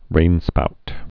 (rānspout)